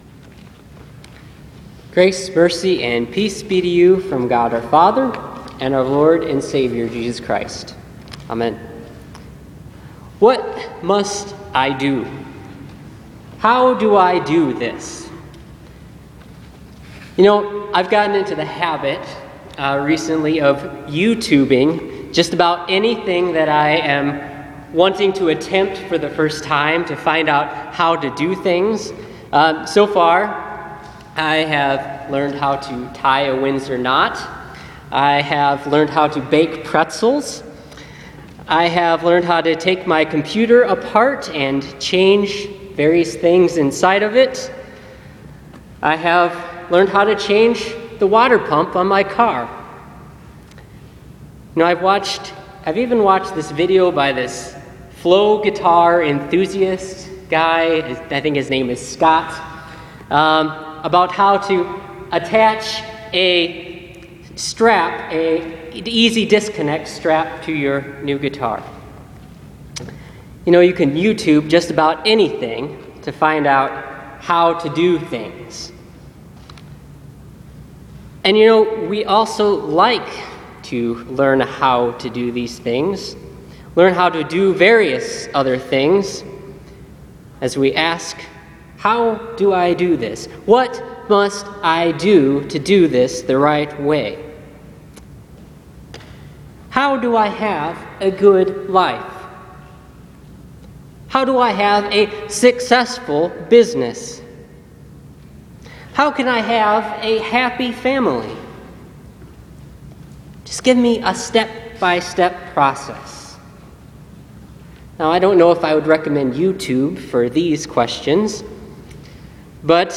The Sermon for this week is from the Gospel lesson Luke 10:25-37.